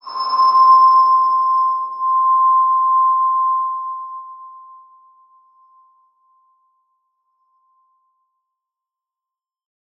X_BasicBells-C4-mf.wav